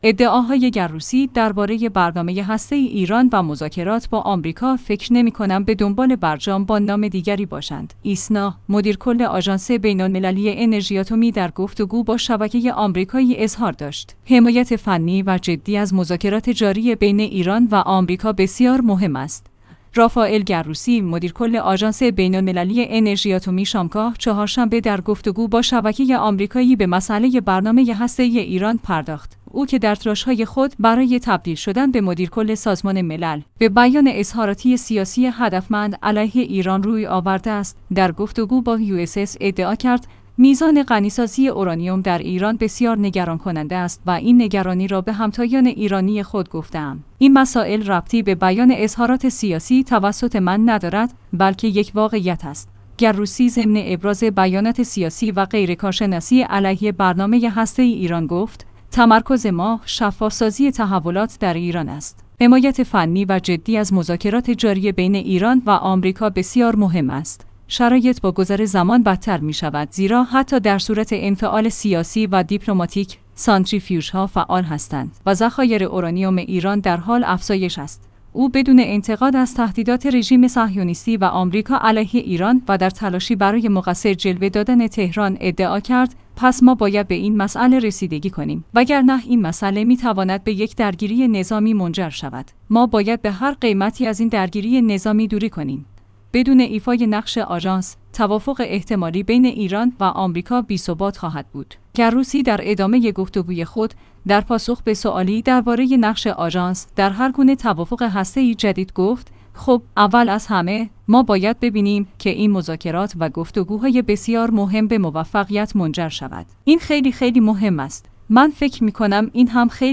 «رافائل گروسی» مدیرکل آژانس بین‌المللی انرژی اتمی شامگاه چهارشنبه در گفت‌وگو با شبکه آمریکایی به مسئله برنامه هسته‌ای ایران پرداخت.